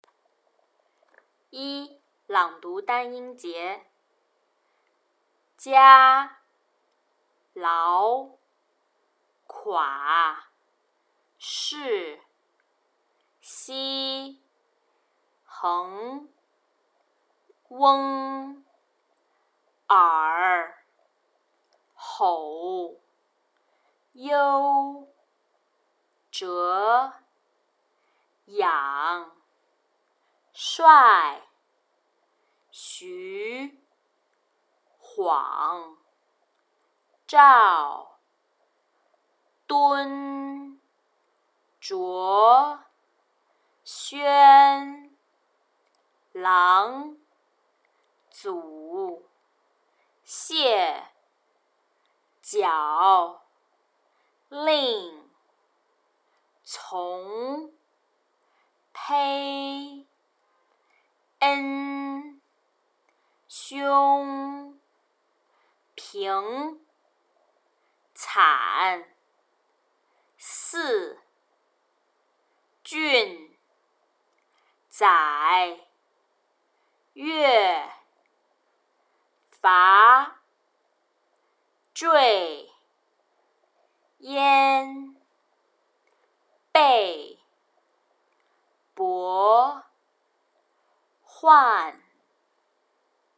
Tasks 1&2 Word Reading
Beijing Sample: